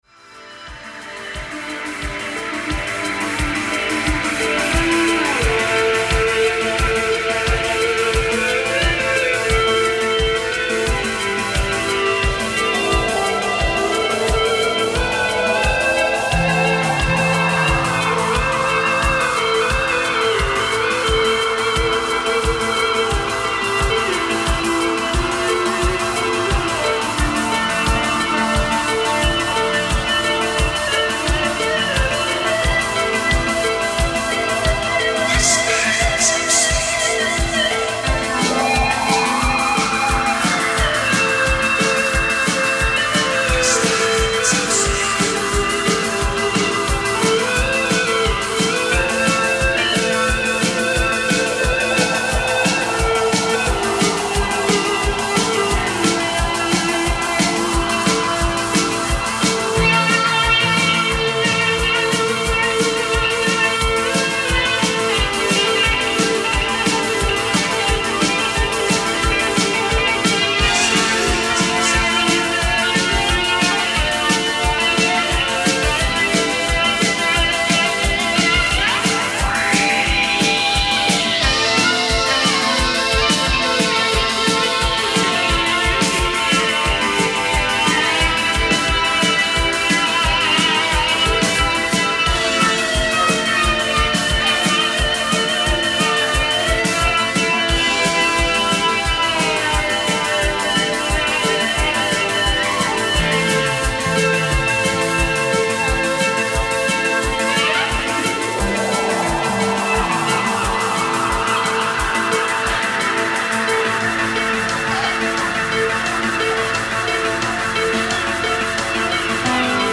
desk recording
along with some tasty eBow work